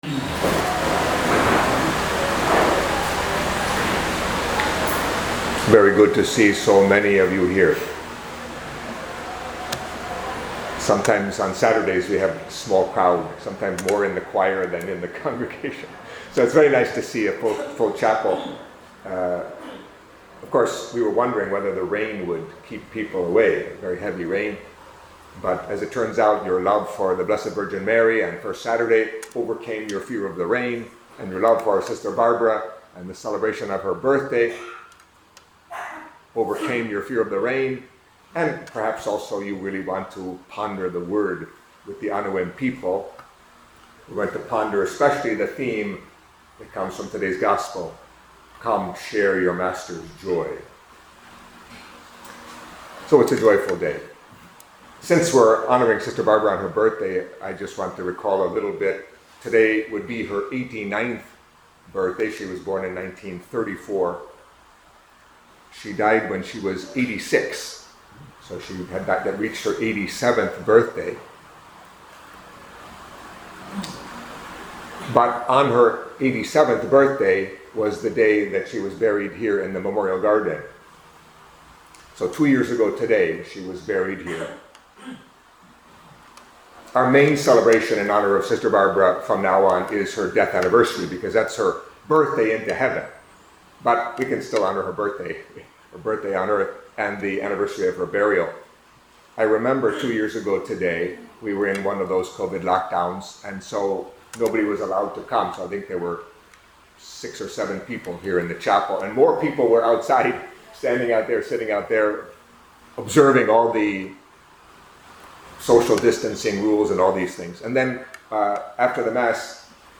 Catholic Mass homily for Saturday of the Twenty-First Week in Ordinary Time